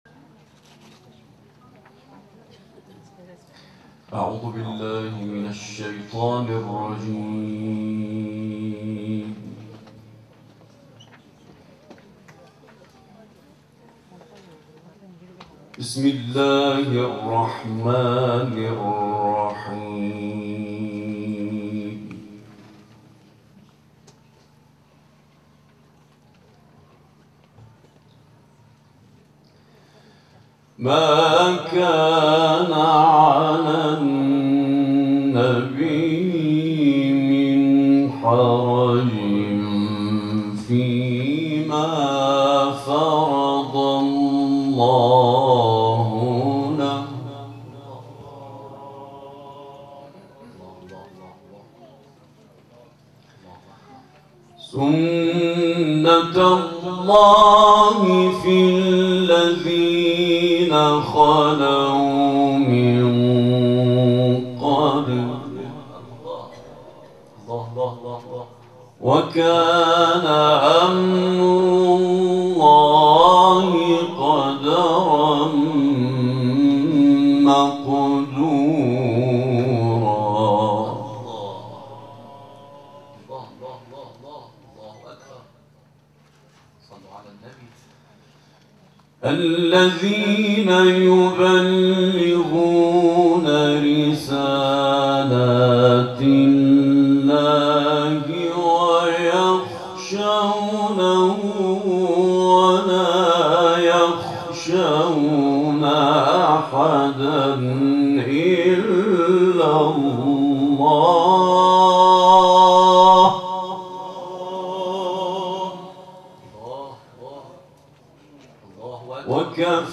تلاوتی از سوره احزاب